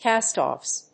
/ˈkæˌstɔfs(米国英語), ˈkæˌstɔ:fs(英国英語)/